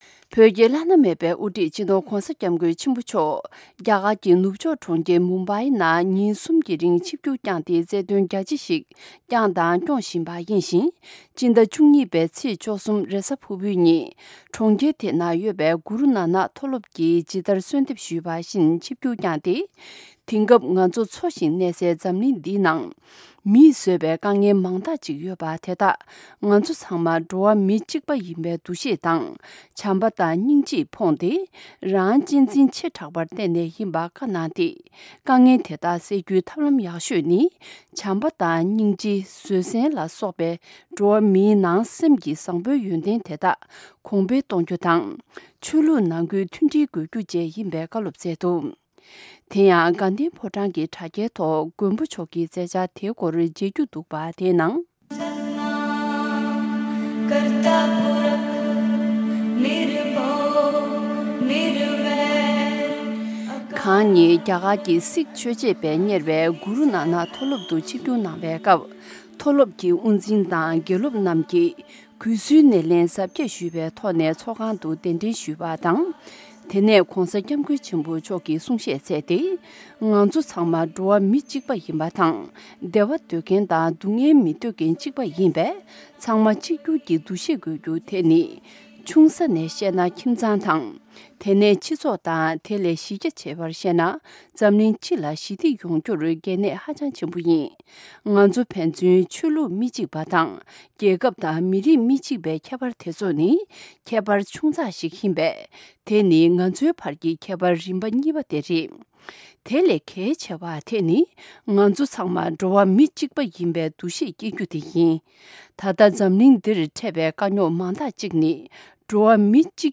གུ་རུ་ནཱ་ནག་མཐོ་སློབ་ཏུ་བྱམས་བརྩེའི་གསུང་བཤད།
བོད་ཀྱི་བླ་ན་མེད་པའི་དབུ་ཁྲིད་སྤྱི་ནོར་༧གོང་ས་༧སྐྱབས་མགོན་ཆེན་པོ་མཆོག་རྒྱ་གར་གྱི་མུམ་བྷེ་གྲོང་ཁྱེར་དུ་ཚུགས་པའི་གུ་རུ་ནཱ་ནག་མཐོ་སློབ་ཀྱིས་ཇི་ལྟར་གསོལ་བ་བཏབ་པ་ལྟར་ཕྱི་ཟླ་༡༢་པའི་ཚེས་༡༣་ཉིན་མཐོ་སློབ་དེར་ཆིབས་སྒྱུར་བསྐྱངས་ནས་བྱམས་བརྩེ་དང་ཀུན་ཁྱབ་འགན་ཁུར་བཅས་ཀྱི་བརྗོད་གཞིའི་ཐོག་ཏུ་གསུང་བཤད་གནང་ཡོད་ཅིང་། གསུང་བཤད་དེར་ཆོས་ལུགས་མཐུན་སྒྲིལ་དང་ཁོར་ཡུག་སྲུང་སྐྱོབ། བོད་དོན་དང་རྒྱ་གར་གྱི་གནའ་བོའི་ལེགས་བྱང་རྣམས་སླར་གསོ་བྱ་རྒྱུ་བཅས་